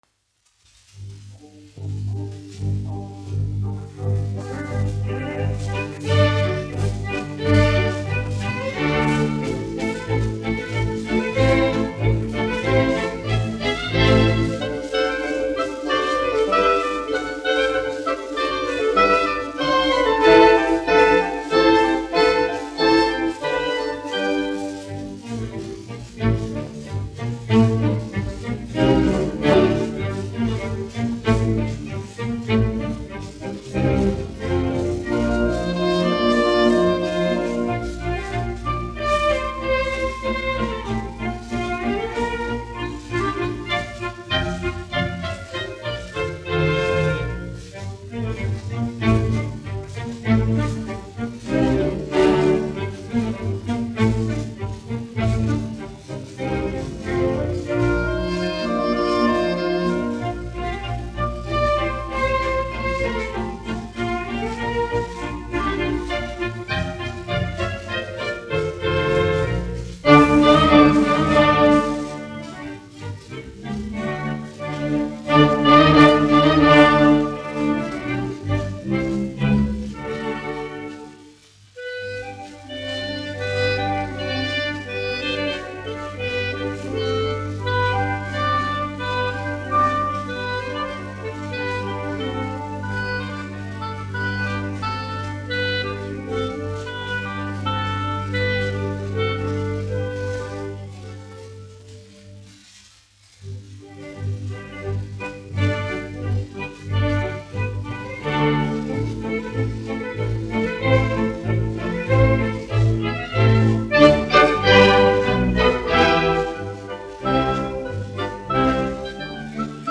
1 skpl. : analogs, 78 apgr/min, mono ; 25 cm
Skatuves mūzika
Orķestra mūzika
Skaņuplate